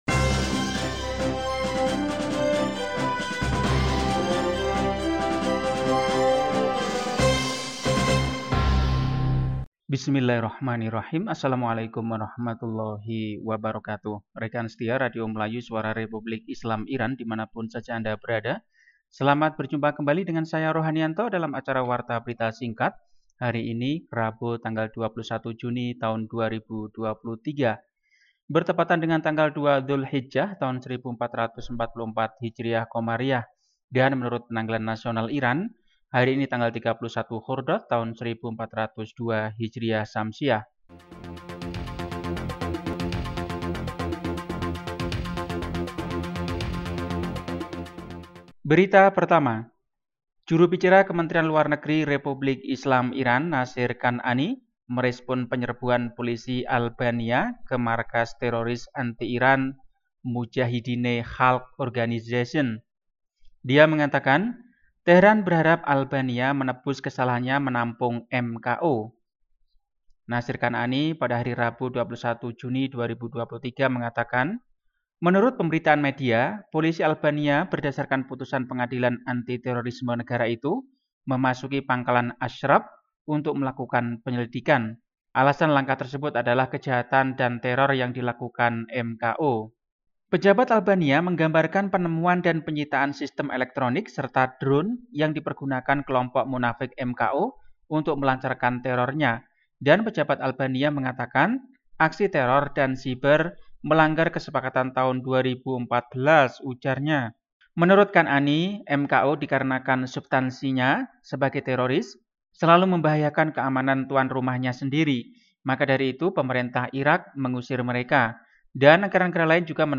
Warta berita, Rabu, 21 Juni 2023.